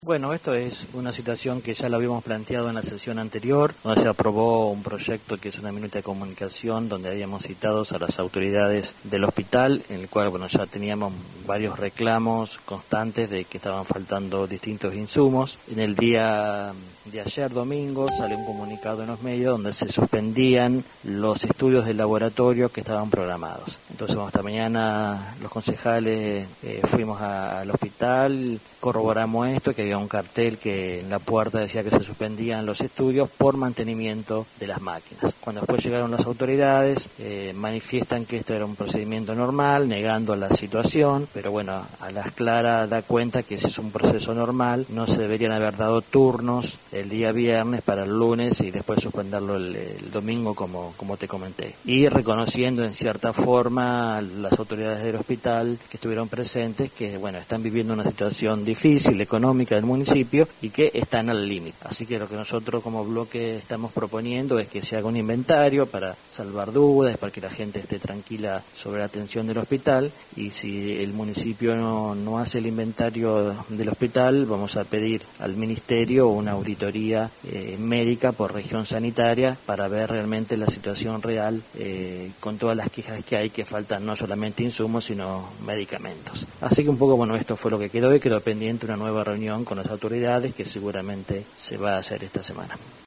Se adjunta audio del concejal Javier Estévez